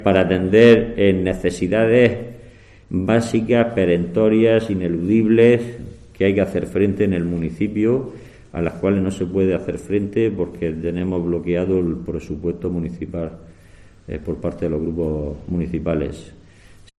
Abellán, concejal Hacienda Ayto Lorca